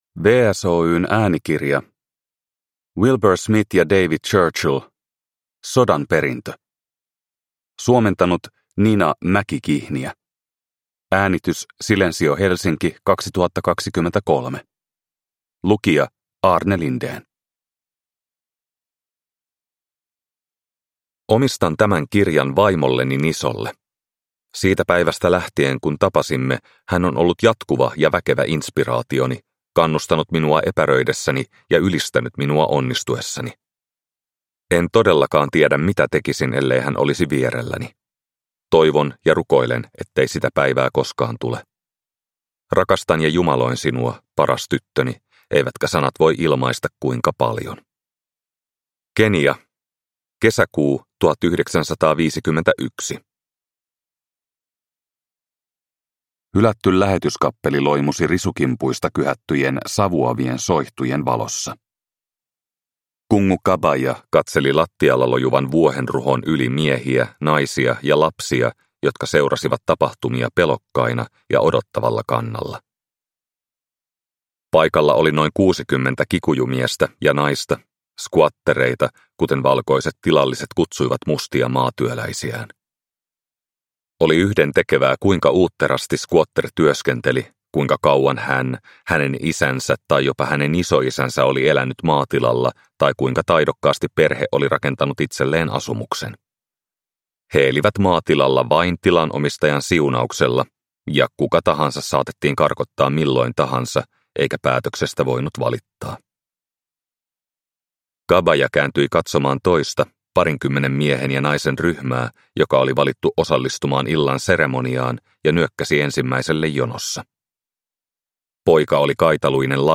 Sodan perintö – Ljudbok – Laddas ner